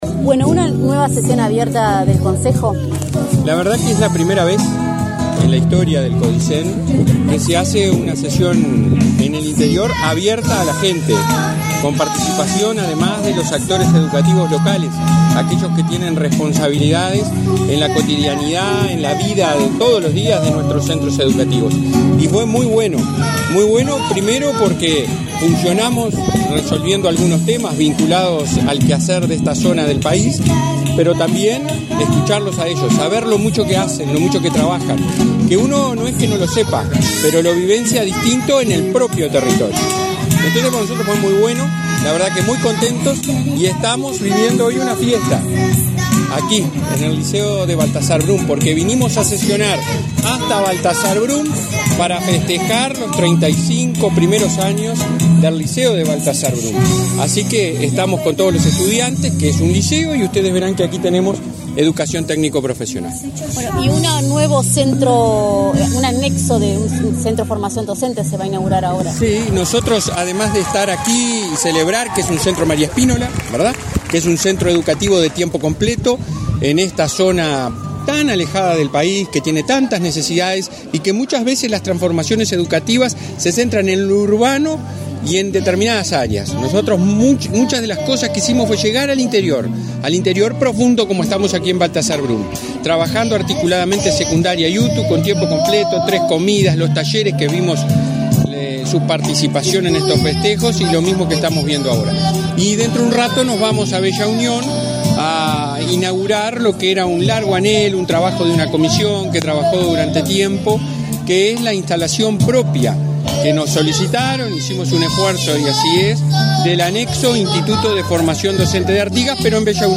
Entrevista al presidente de la ANEP, Robert Silva
El presidente de la Administración Nacional de Educación Pública (ANEP), Robert Silva, participó, este 16 de mayo en una sesión abierta organizada por el Consejo Directivo Central de ese organismo en la localidad de Baltasar Brum. En la oportunidad, informó a Comunicación Presidencial sobre la inauguración del anexo del Instituto de Formación Docente de Artigas, en Bella Unión.